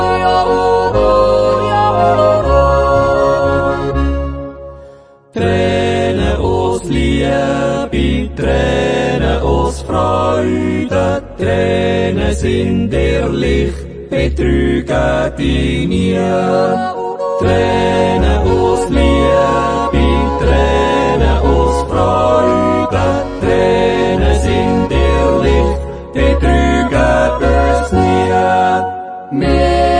Jodellieder, Naturjodel, Ratzliedli